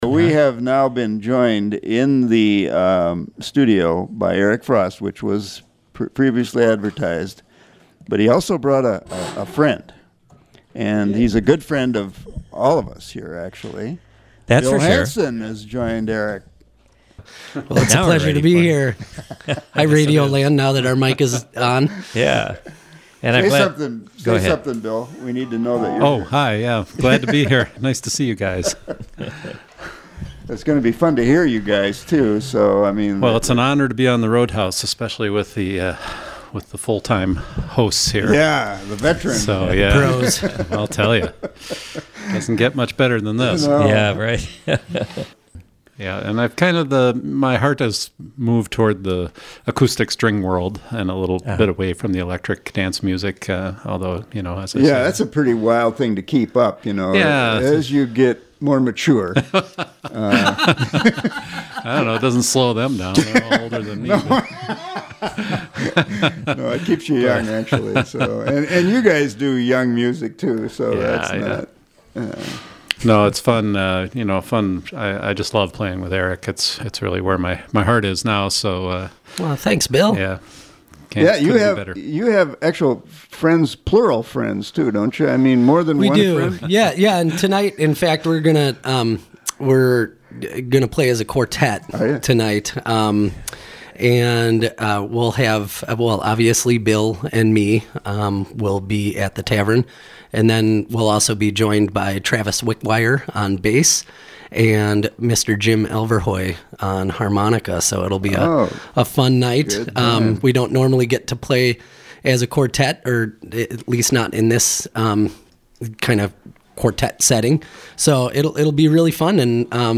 guitar
dobro
Program: Live Music Archive The Roadhouse